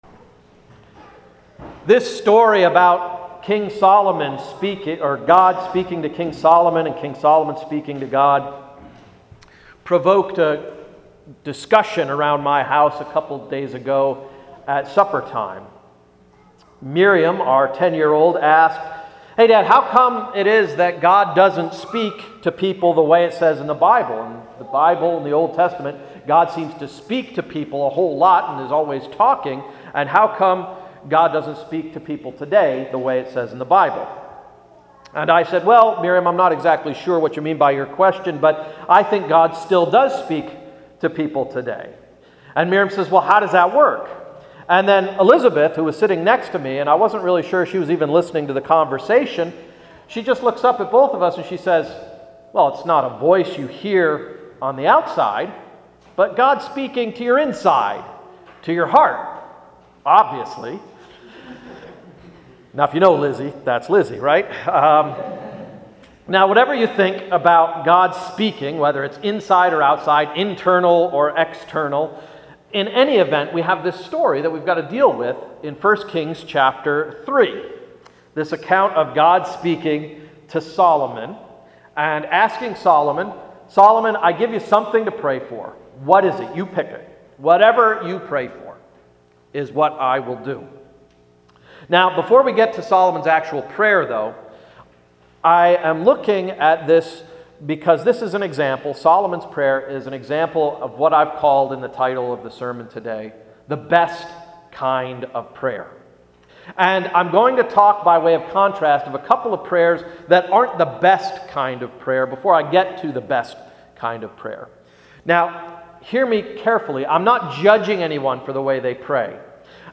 Sermon of August 19, 2012–“The Best Kind of Prayer”